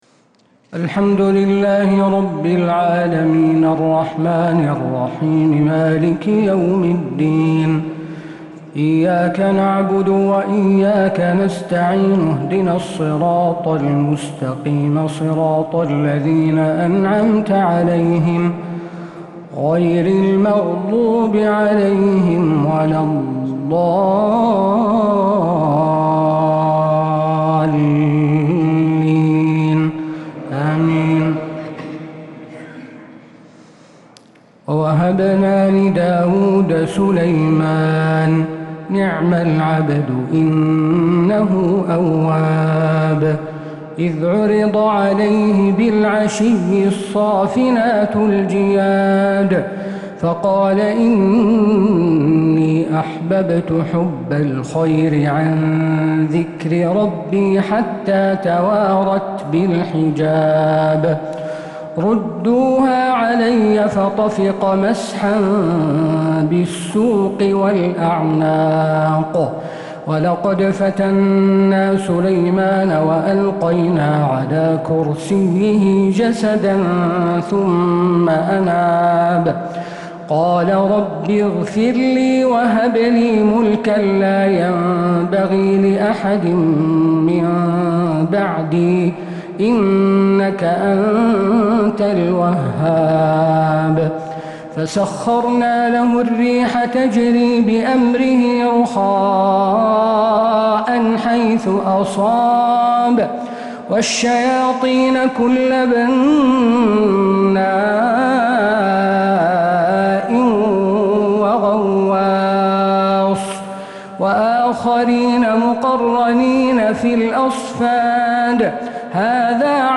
تراويح ليلة 25 رمضان 1446هـ من سورتي ص (30-88) والزمر (1-21) | taraweeh 25th night Ramadan 1446H Surah Saad and Az-Zumar > تراويح الحرم النبوي عام 1446 🕌 > التراويح - تلاوات الحرمين